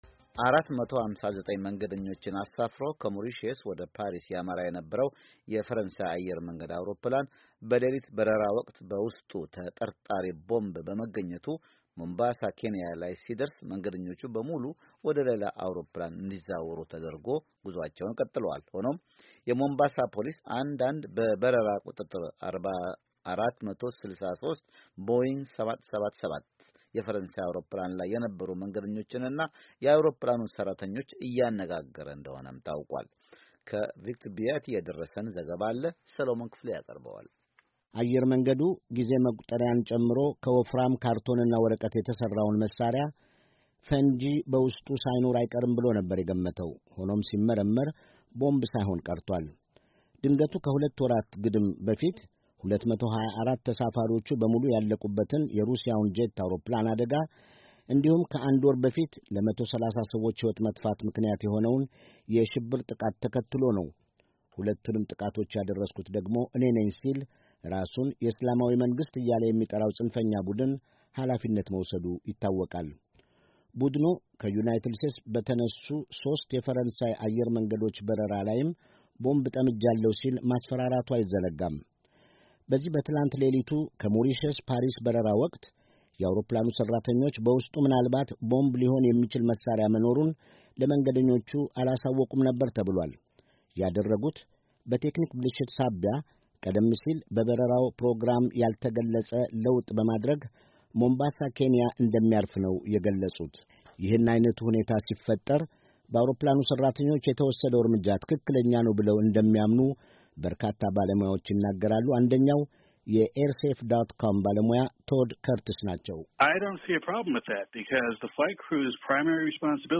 የደረሰን አጭር ዘገባ አለ።